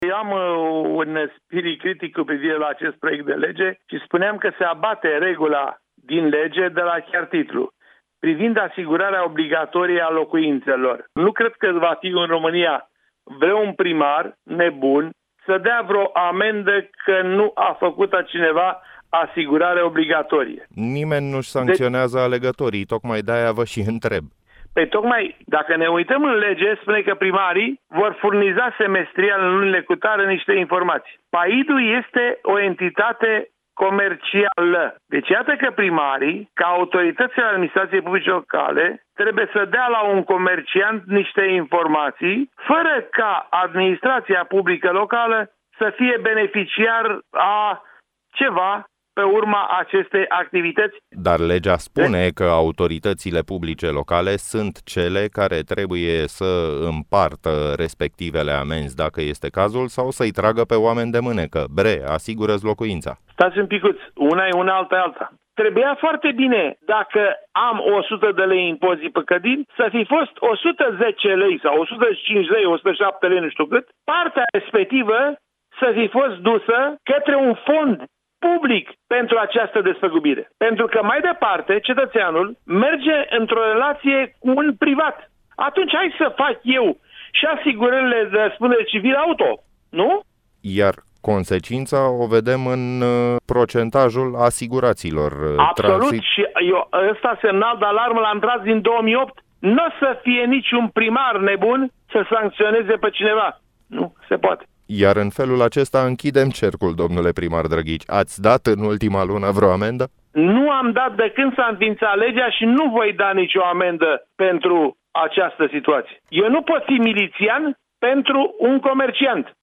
De ce s-a ajuns în această situație, a explicat Emil Drăghici, primarul Vulcana-Băi, județul Dâmbovița, în cadrul emsiunii Drum cu prioritate, la Europa FM.